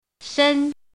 怎么读
shēn
shen1.mp3